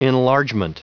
Prononciation du mot enlargement en anglais (fichier audio)
Prononciation du mot : enlargement